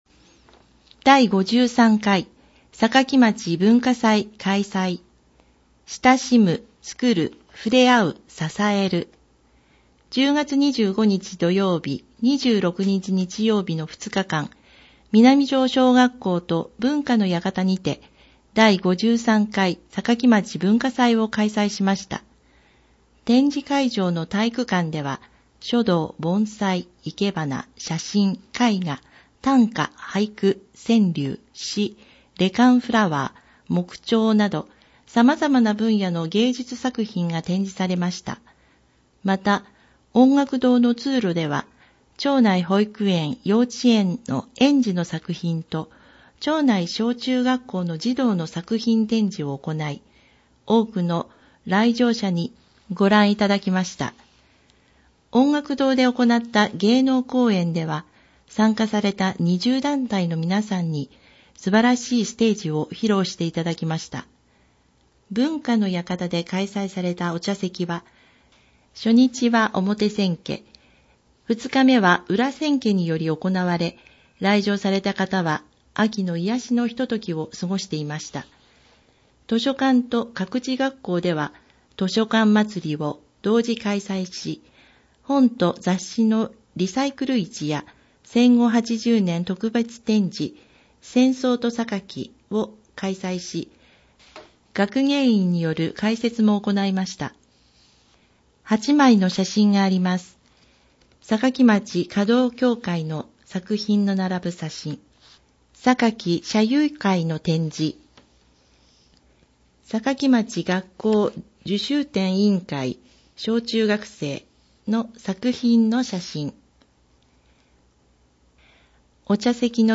音訳版ダウンロード(制作：おとわの会）